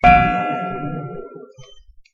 extinguisher_touch.1.ogg